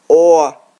File File history File usage Trây_oa.ogg (file size: 15 KB, MIME type: application/ogg ) Trây /oə/ File history Click on a date/time to view the file as it appeared at that time.